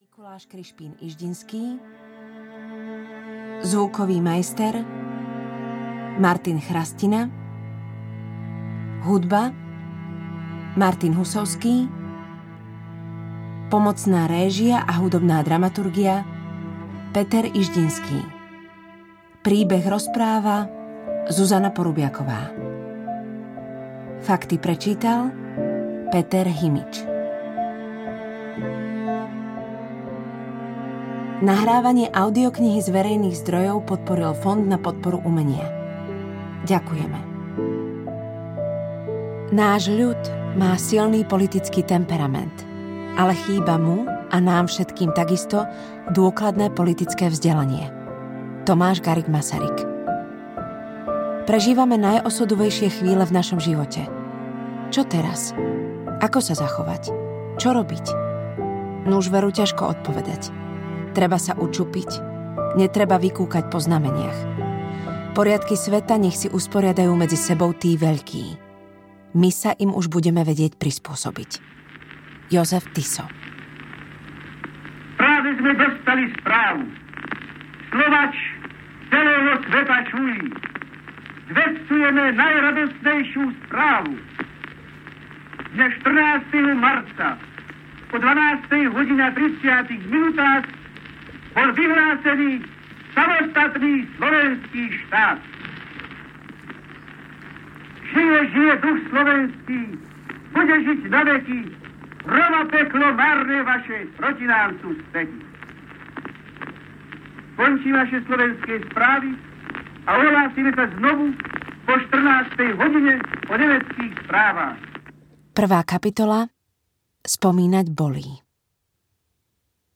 Nedeľné šachy s Tisom audiokniha
Ukázka z knihy
nedelne-sachy-s-tisom-audiokniha